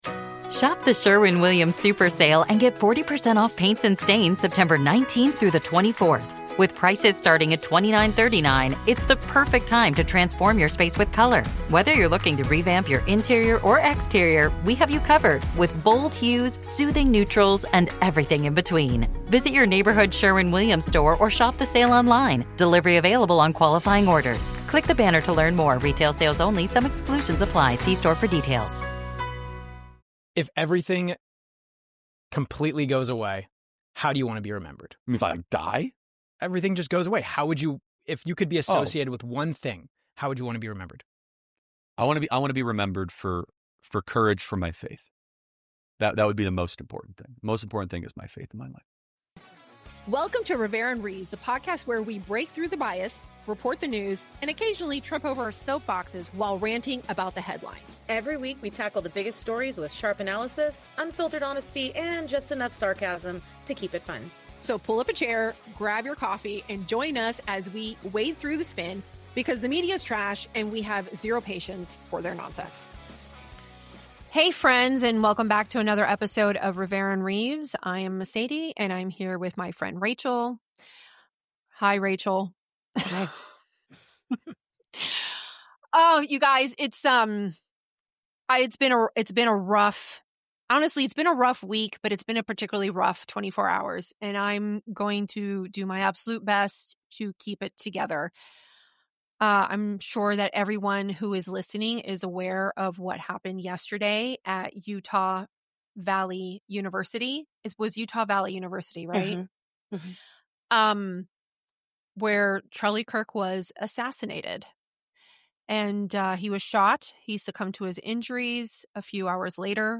Recording just hours after the tragic events unfolded, the hosts grapple with what this means for America, political discourse, and the rising tide of violence targeting those who dare to speak truth on college campuses. From their initial reactions to the media's coverage, from Trump's Oval Office address to the ongoing manhunt - this is an unfiltered conversation about a moment that changed everything.